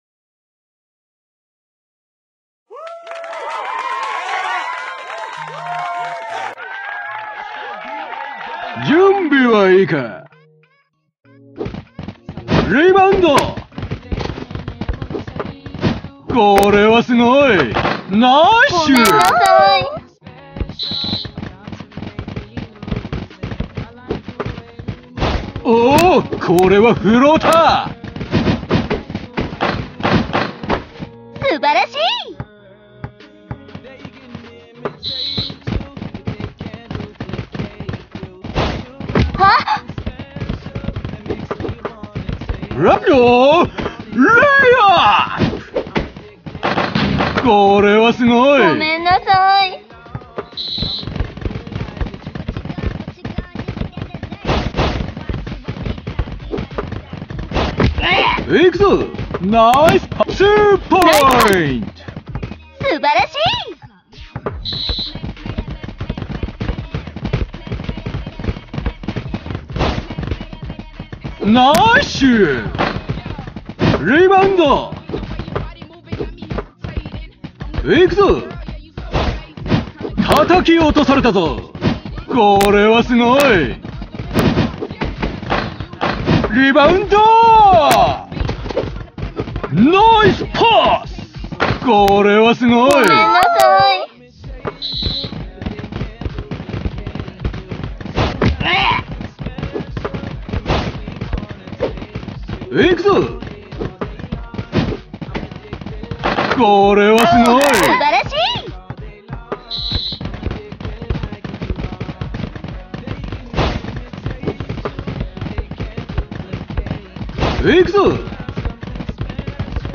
3on3対戦ゲーム、アプリ《フィーバーダンク》のプレイ動画です。 実況は無しの試合している光景だけになりますのでご了承を。